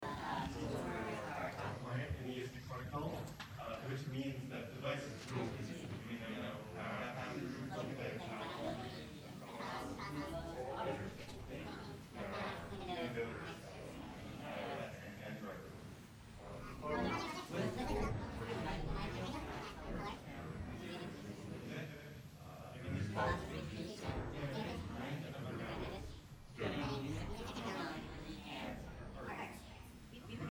以下の例では「12:13」というテンポを同時に走らせて、10.9秒後に停止させている。